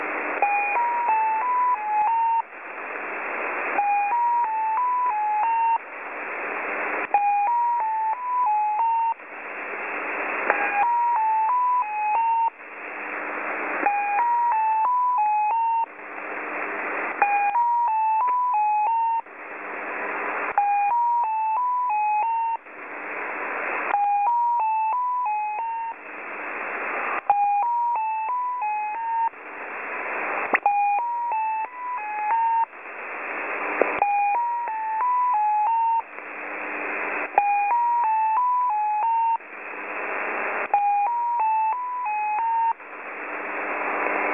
Recordings of digital numbers stations
X06 - Mazielka, selcal system for diplomatic stations